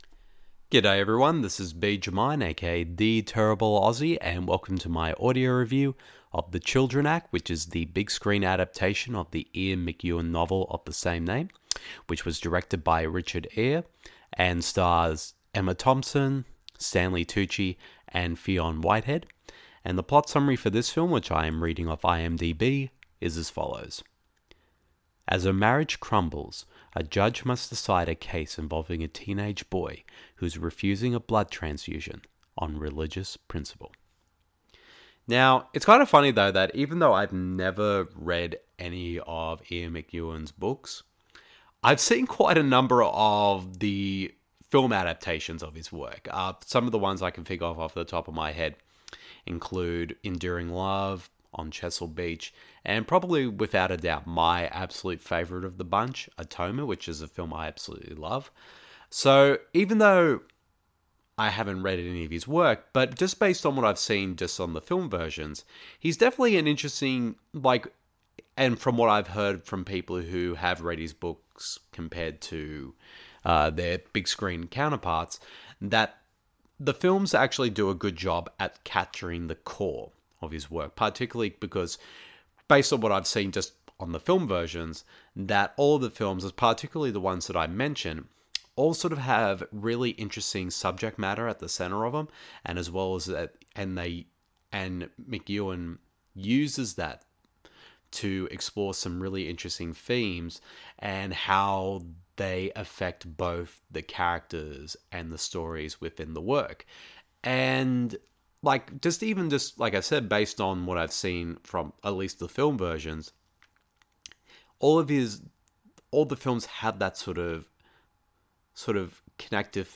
The following review of the film is in an audio format.